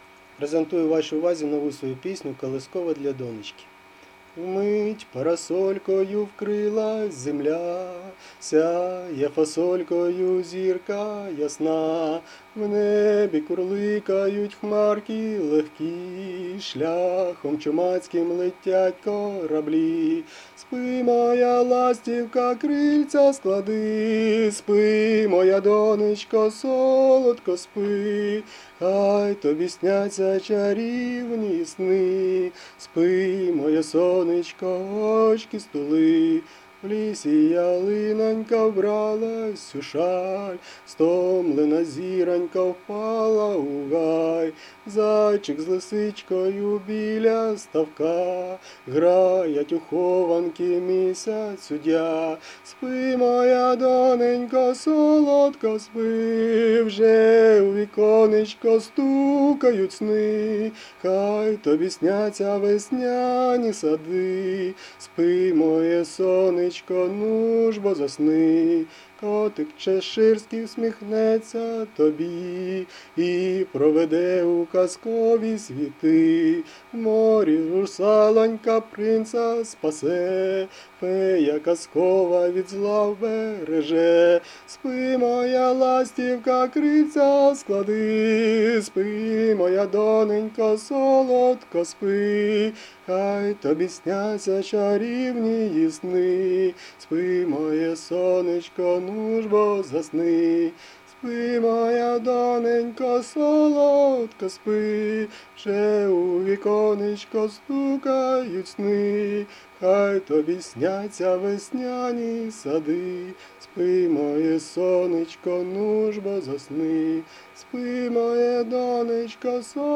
Рубрика: Поезія, Авторська пісня
Розгарна колискова! love18 angel kiss